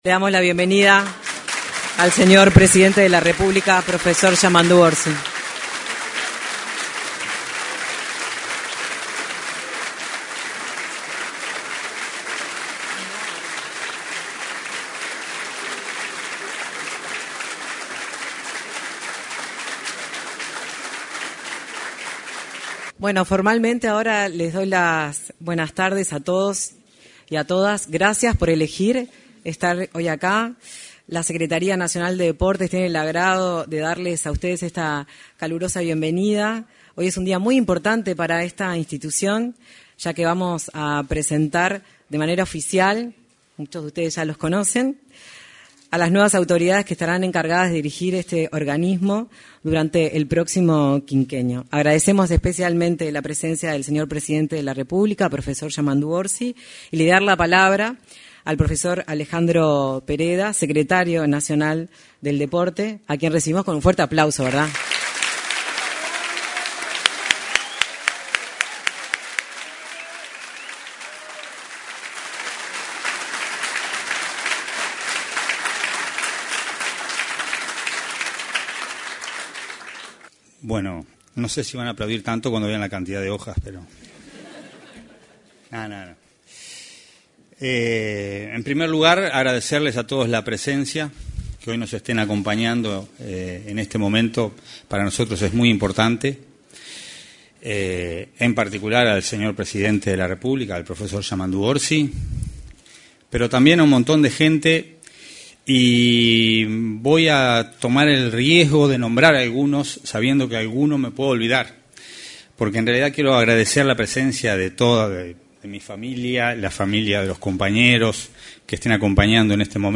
Asunción de autoridades de la Secretaría Nacional del Deporte 11/03/2025 Compartir Facebook X Copiar enlace WhatsApp LinkedIn Este martes 11 de marzo, en el salón de actos de la Torre Ejecutiva, con la presencia del presidente de la República, profesor Yamandú Orsi, se efectuó la asunción de las autoridades de la Secretaría Nacional del Deporte. En la oportunidad, se expresó el secretario nacional del Deporte, Alejandro Pereda.